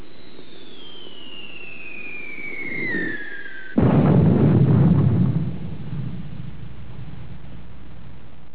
ShellExplode.au